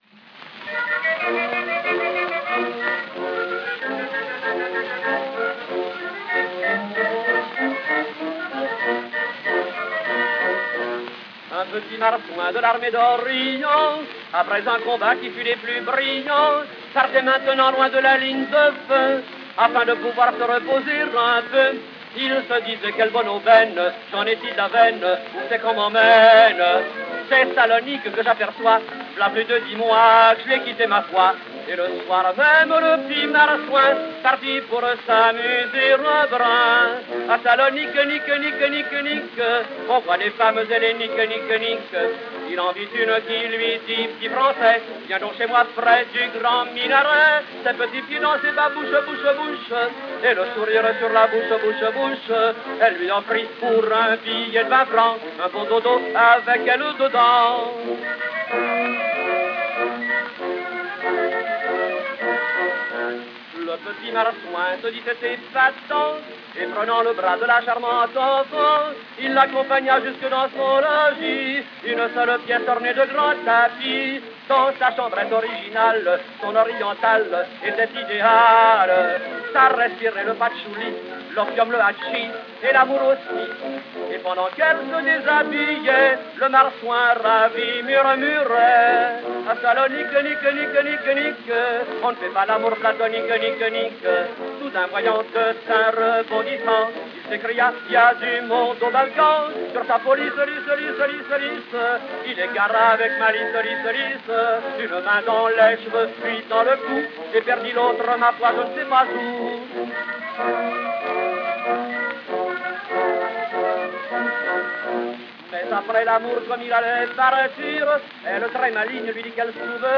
chanson coquine